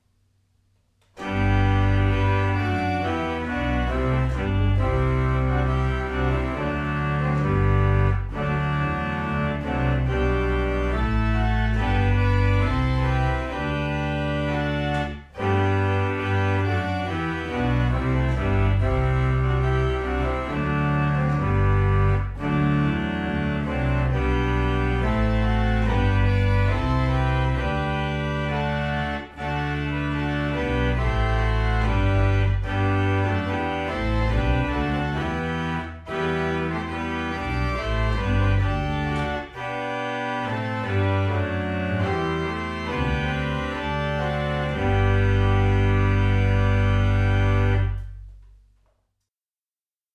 This is a Baroque-style tracker action organ containing 40 ranks of pipes numbering about 2500.
*** More Sound Samples of the Boomeria Organ:
GREAT CHORALES: Helmsley, In Dir Ist Freude, Psalm 42.